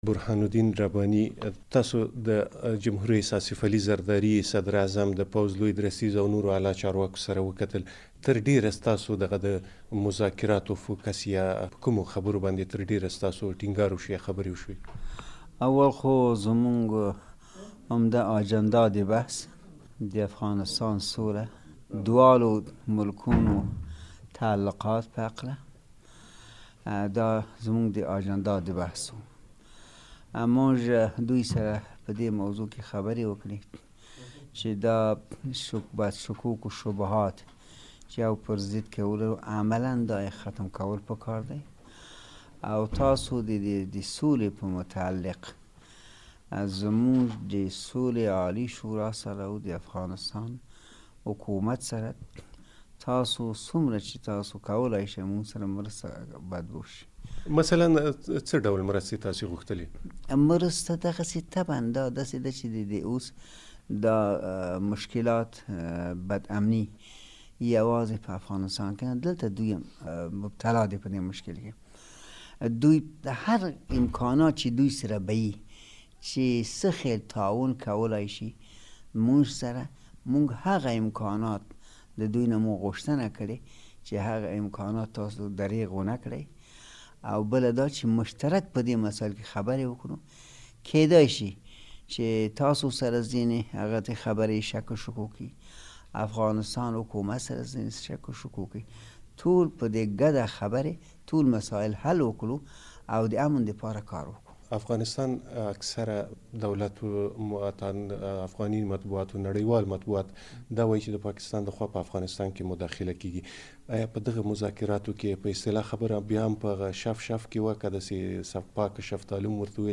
د سولې د عالي شورا له مشر ښاغلي رباني سره مرکه واورئ